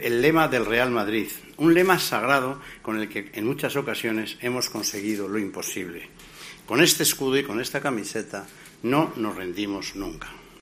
Florentino Pérez, presidente del Real Madrid, afirmó este lunes, durante el acto de presentación de 'Real, el concierto', que se celebrará el 15 de febrero de 2018 en el Teatro Real con motivo del vigésimo aniversario de la Fundación del club, que la entidad que lidera "ha creado la leyenda más grande de la historia del deporte".